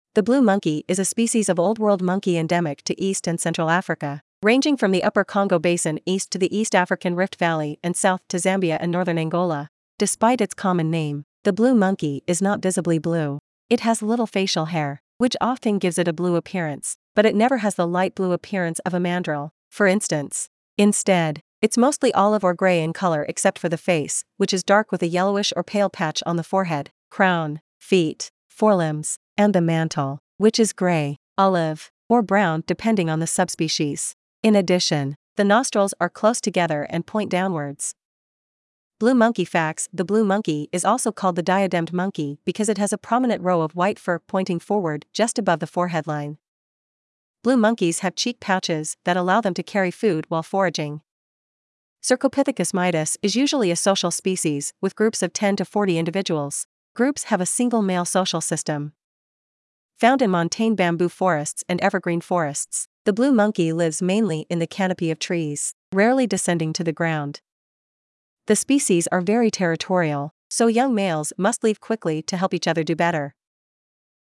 • Common Name: Blue Monkey
This species of monkey is very vocal and loud.
Blue-monkey.mp3